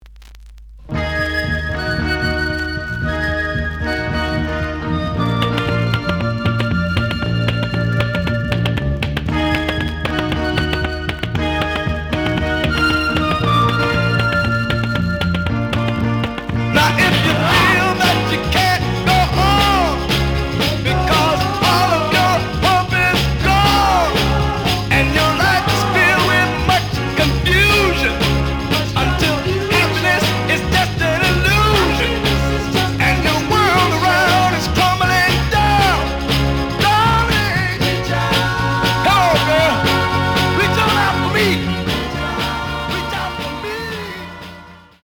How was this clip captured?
The audio sample is recorded from the actual item. Some noise on beginning of A side, but almost plays good.)